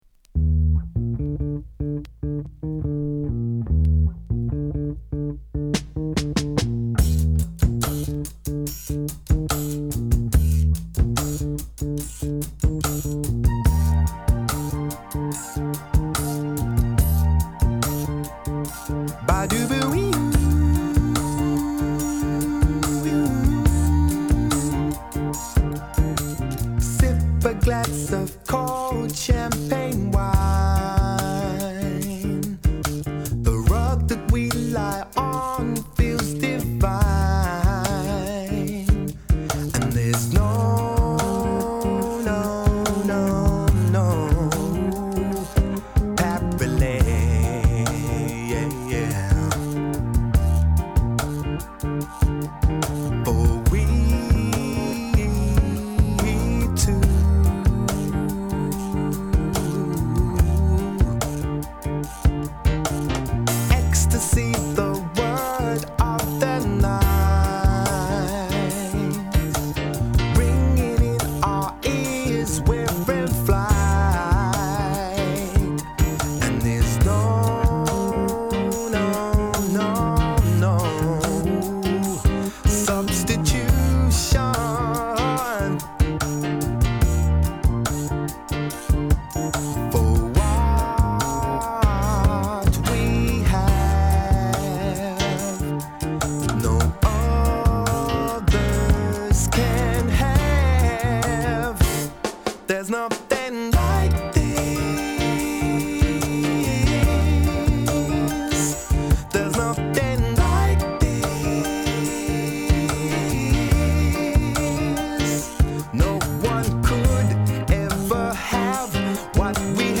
Ragga Grunge Mix Dj Version
Love Mix Original Version *